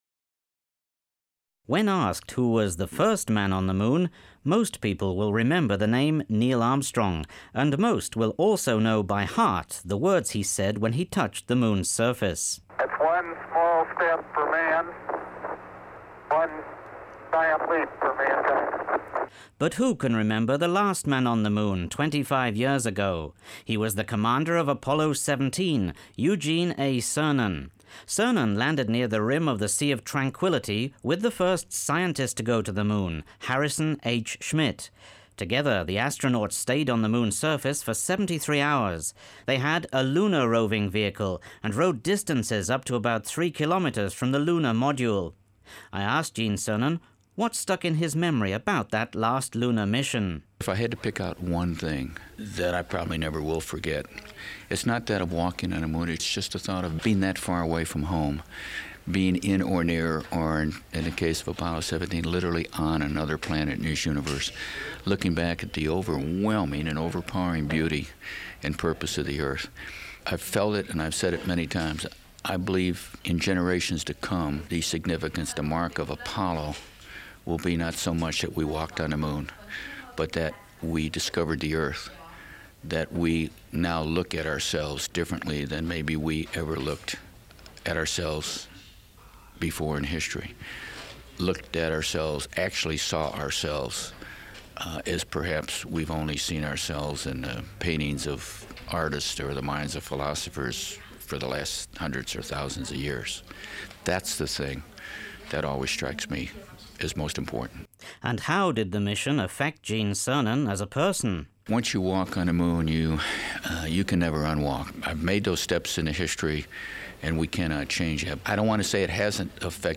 talks with Eugene Cernan in 1997, 25 years after the Apollo 17 mission.